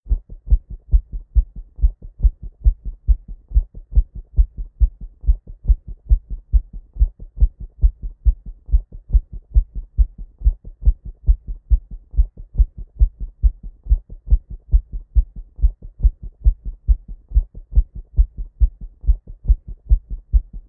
Звуки полиграфа
Звук нервничает, слышно по сердцу